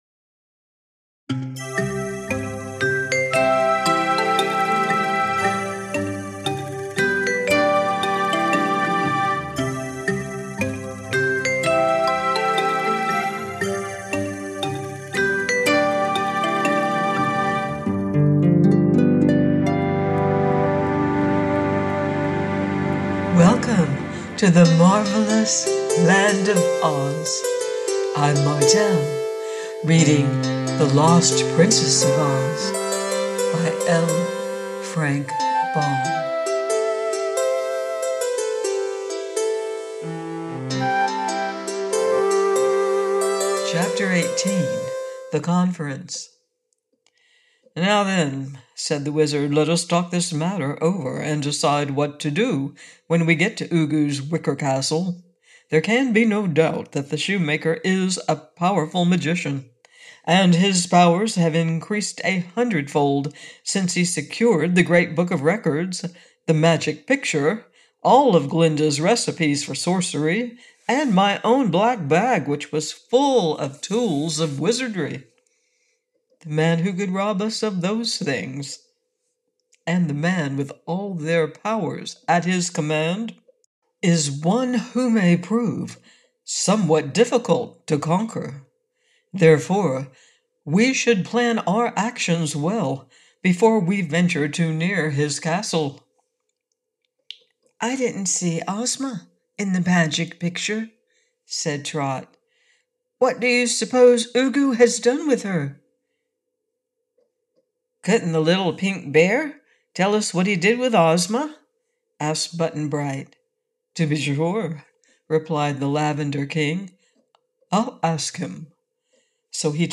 The Lost Princess of Oz by Frank L. Baum - AUDIOBOOK